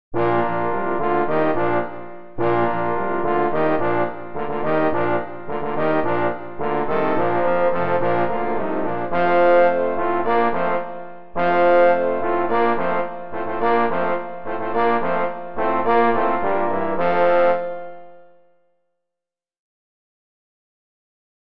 for trombone octet
This abridged version is arranged for eight trombones